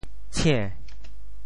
How to say the words 抢 in Teochew？
TeoChew Phonetic TeoThew ciên2 白